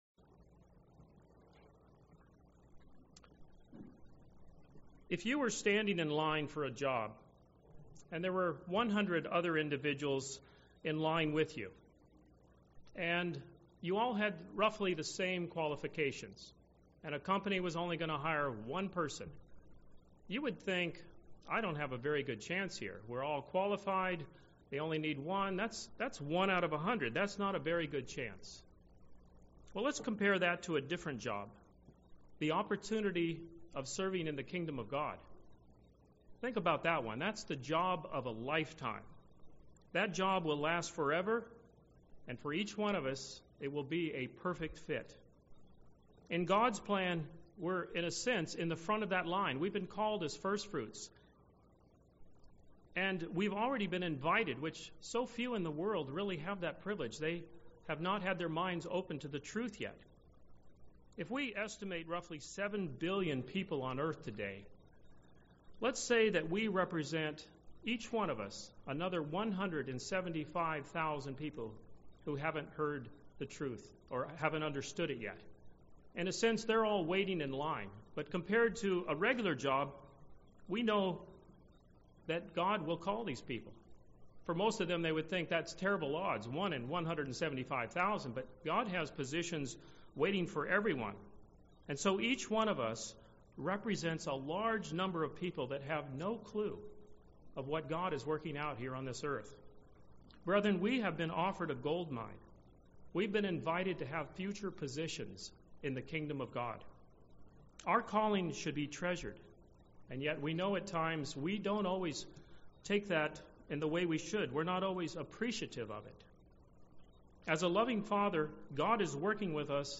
Have you considered that your congregation provides various opportunities for growth? This sermon discusses three points on why it is vital to be joined to a congregation.
Given in Orlando, FL